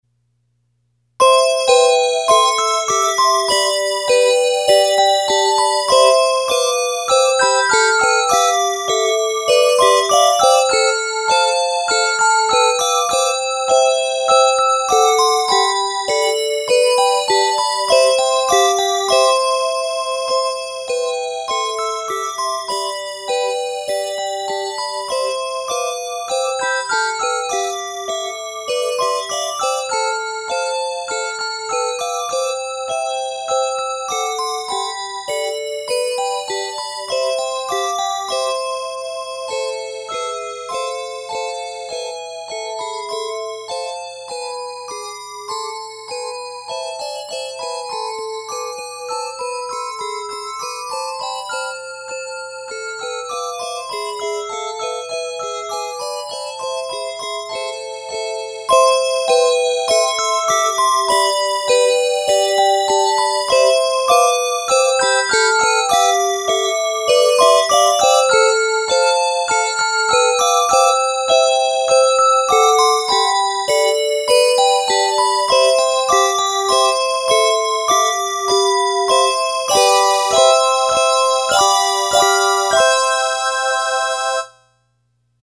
has a lively, toe-tapping feel
Key of C Major and a minor.